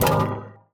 UIClick_Menu Select Synth Strong 01.wav